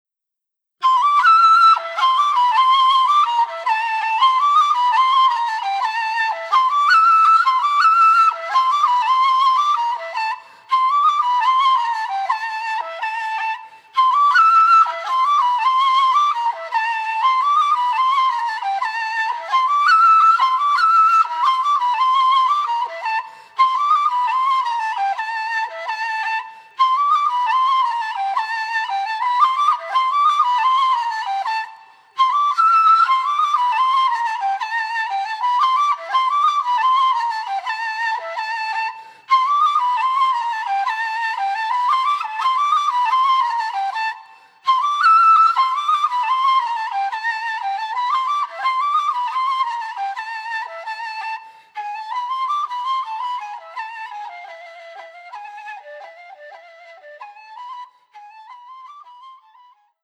traditional Bulgarian kaval music